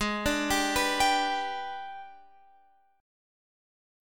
G#mM7b5 Chord